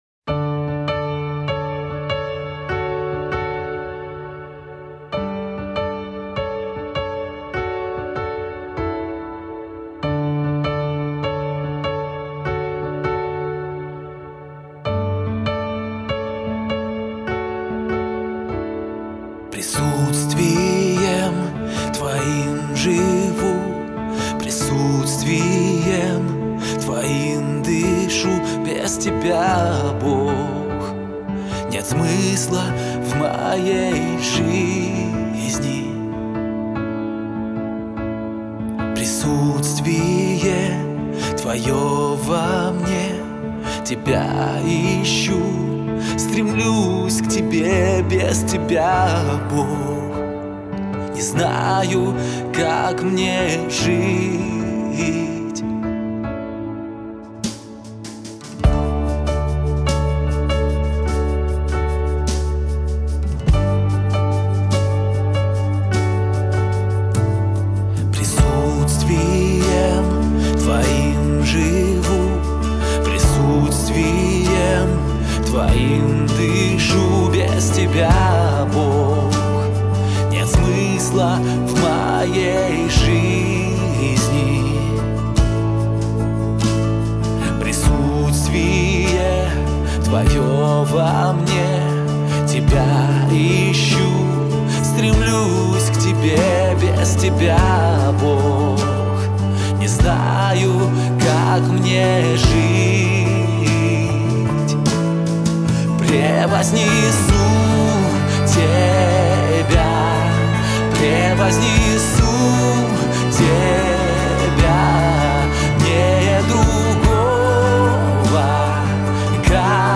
1044 просмотра 485 прослушиваний 60 скачиваний BPM: 148